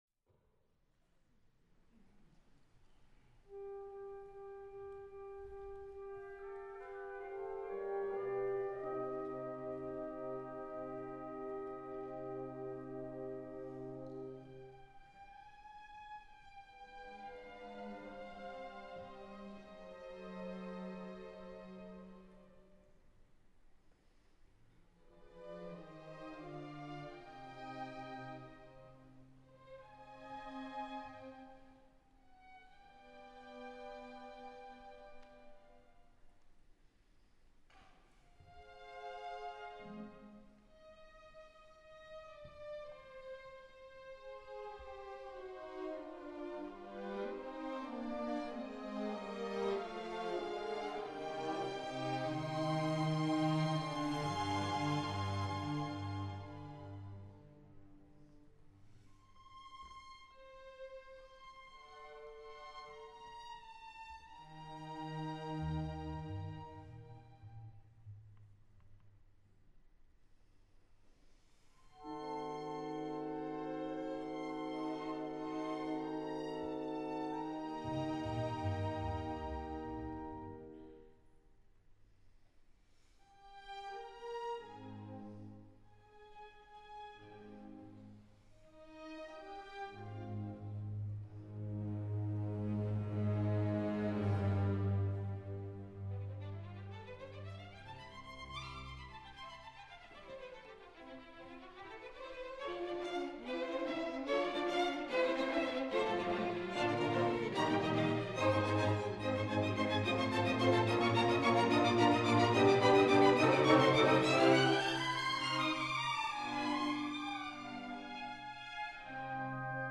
Abonu-denboraldia